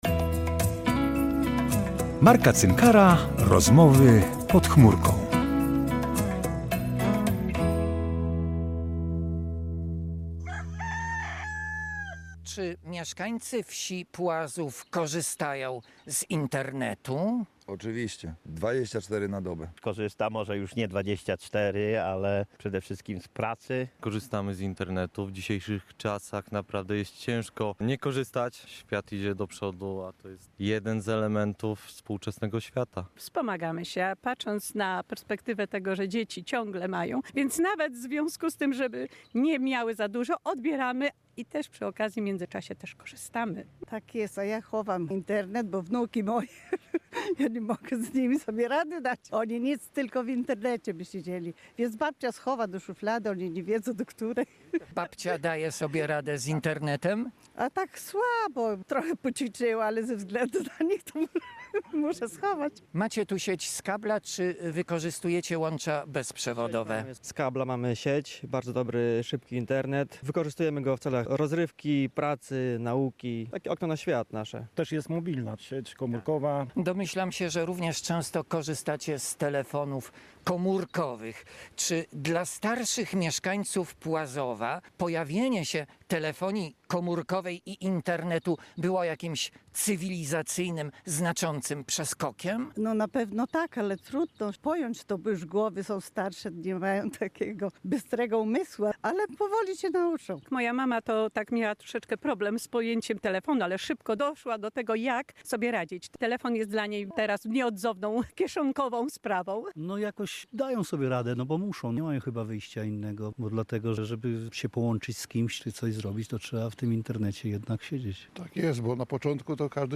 We wsi Płazów, w powiecie narolskim, pytał mieszkańców czy poddali by się podobnemu eksperymentowi? Czy Internet i telefon uzależniają podobnie jak, narkotyki?